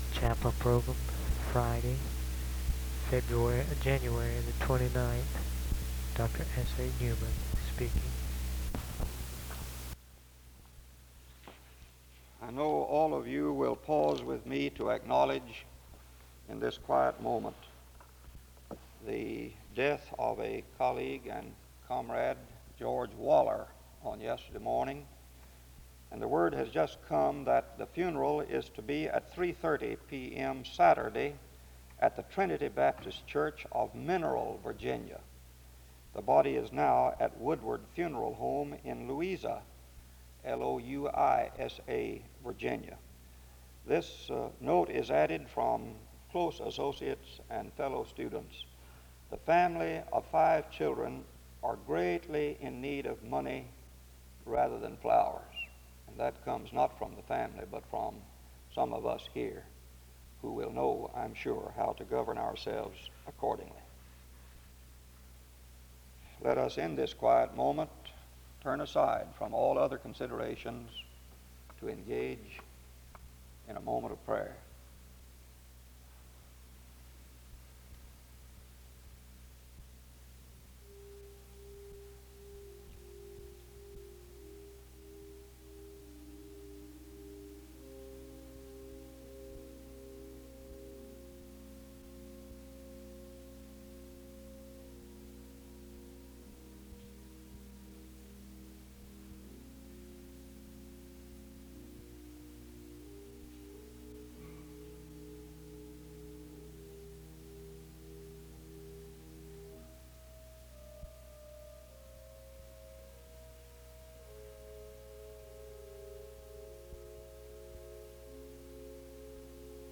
The service begins with an announcement of the passing of a colleague from 0:00-1:15.
Music plays from 2:40-5:29. A responsive reading 5:31-7:12.
He preaches on the desire to know Christ. The choir performs a special song from 22:55-26:29.
The service closes with more music from 26:46-27:24.